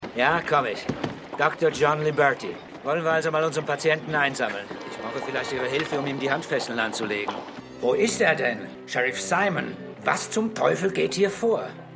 - hat mMn einen hörbaren amerikanischen Einschlag à la Holger Hagen.